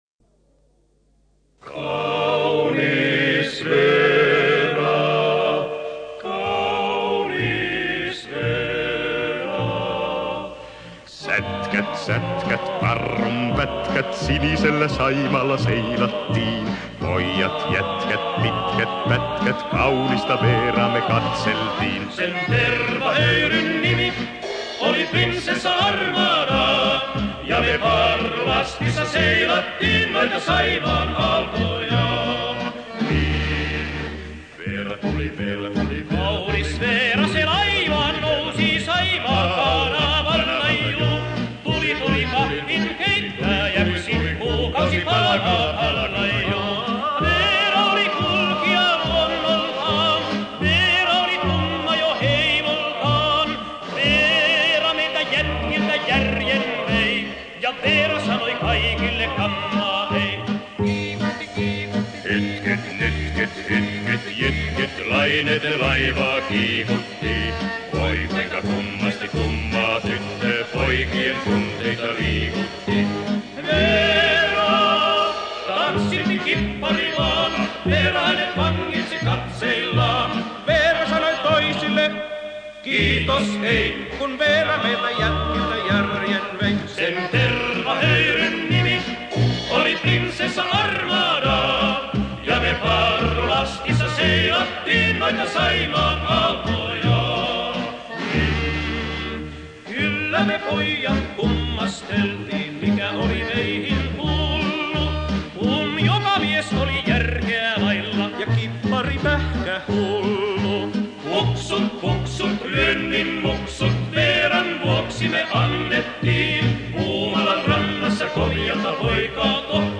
war ein aus vier Sängern bestehendes Quartett